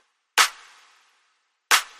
Tennis Hit
Tennis Hit is a free sfx sound effect available for download in MP3 format.
363_tennis_hit.mp3